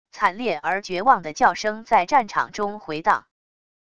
惨烈而绝望的叫声在战场中回荡wav音频